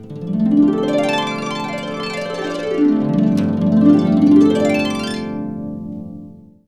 HARP GNX ARP.wav